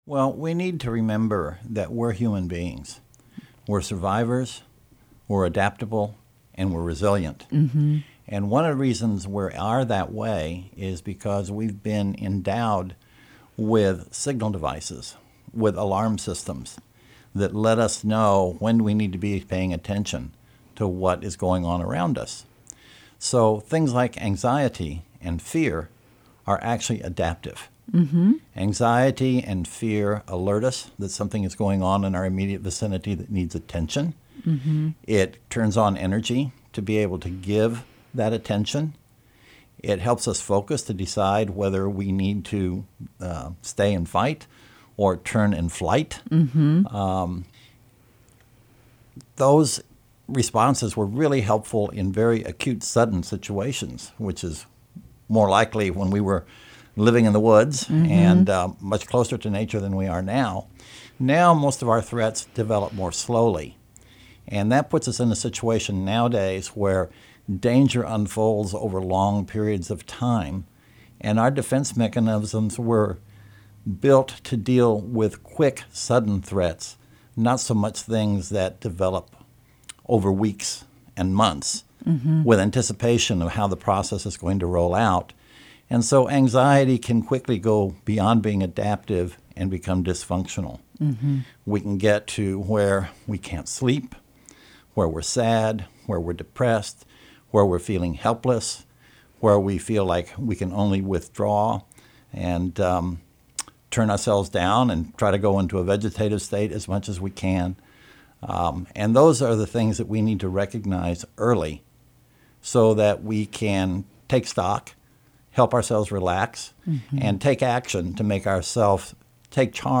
Health Chat